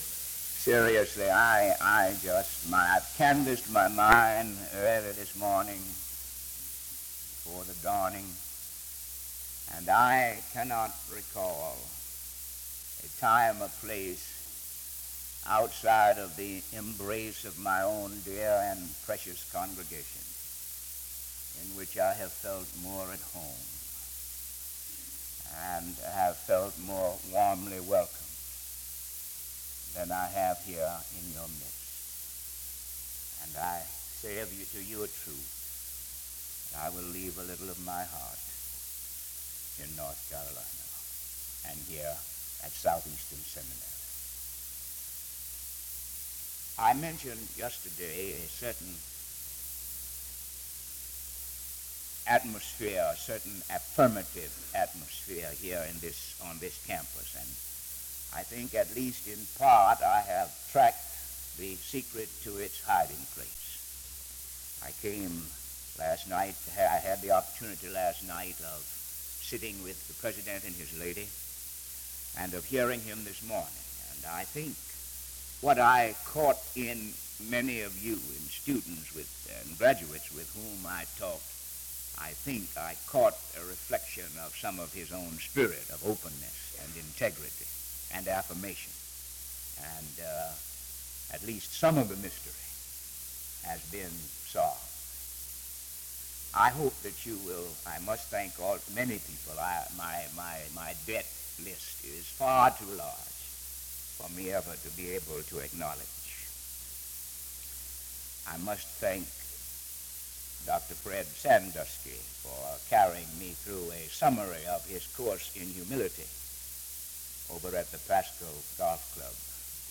SEBTS Adams Lecture - Gardner C. Taylor February 24, 1977